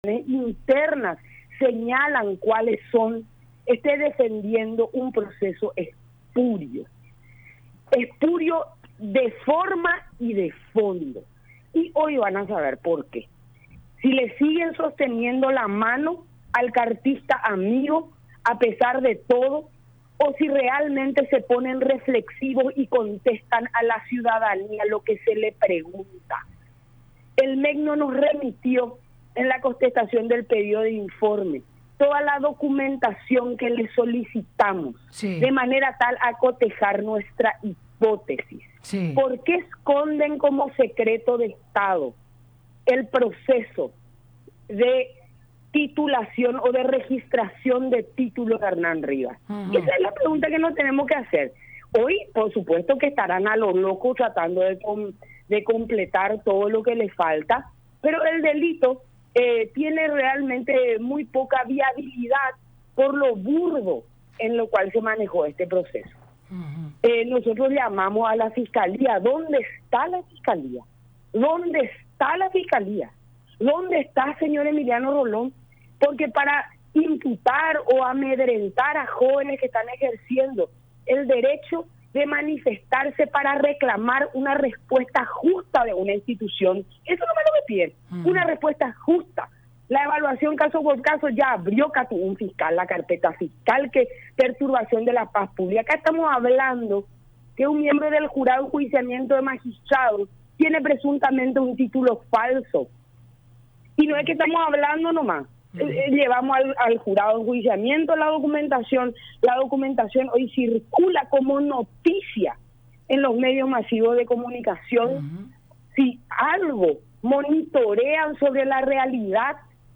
“Hoy estarán a lo loco tratando de completar lo que le falta, pero el delito tiene muy poca viabilidad por lo burdo en lo cual se manejo este proceso”, agregó en el programa “La Mañana De Unión” por radio La Unión y Unión Tv